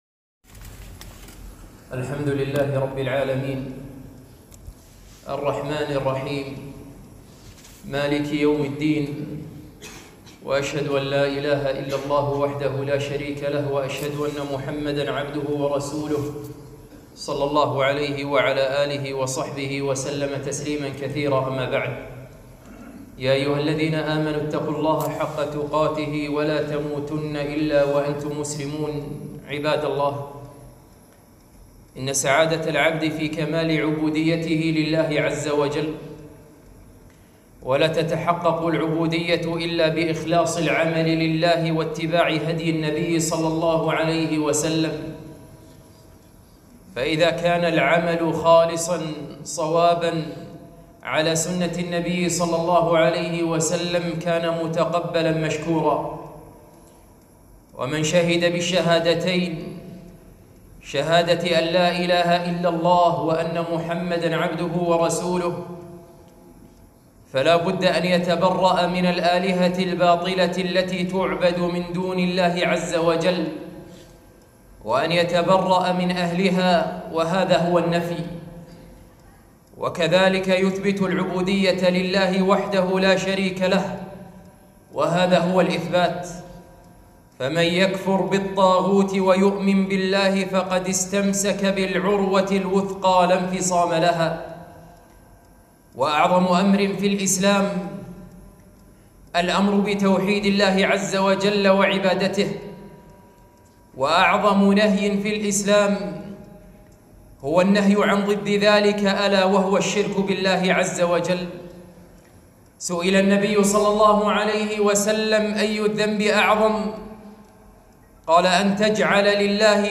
خطبة - كن من أهل التوحيد